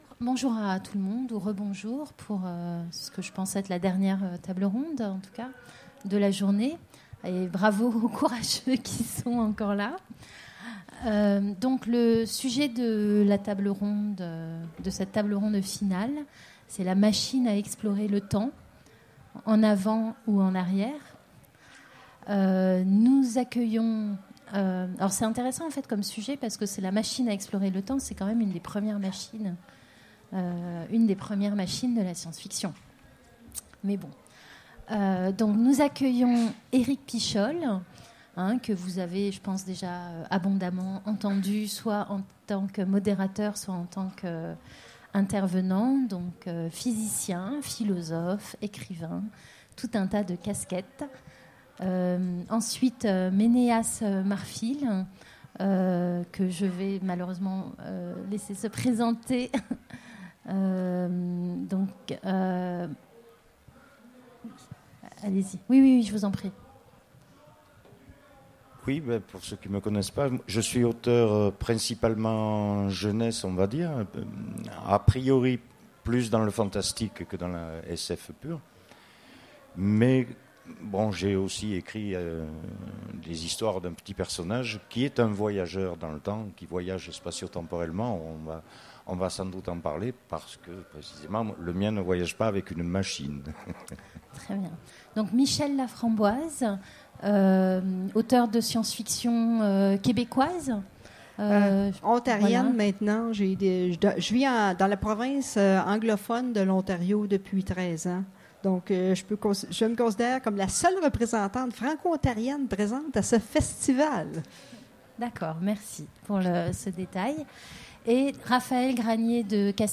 Utopiales 2016 : Conférence La machine à explorer le temps